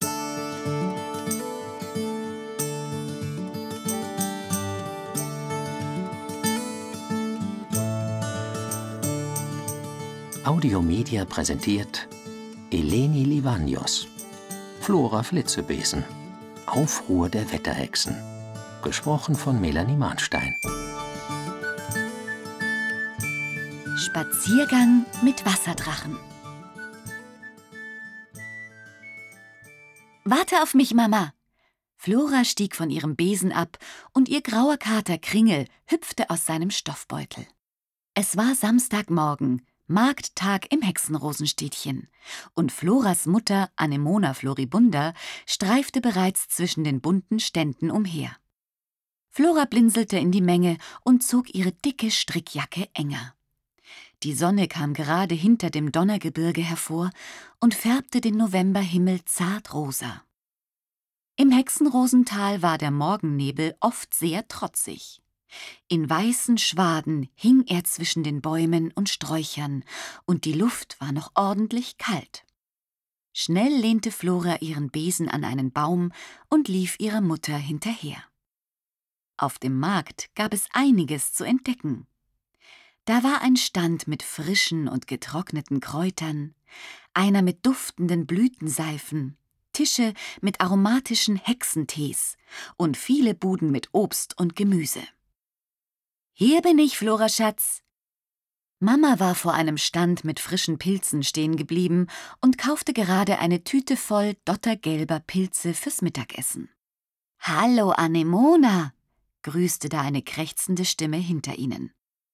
Schlagworte Deutsch • Elfen • Fantasy; Kinder-/Jugendliteratur • Flora • Hexe; Kinder-/Jugendliteratur • Hexen • Hexenmädchen • Hexenrosental • Hilfe • Hörbuch; Lesung für Kinder/Jugendliche • Katasprophe • Katastrophe • Mädchen; Kinder-/Jugendliteratur • Sturm • Wetter • Wetterhexen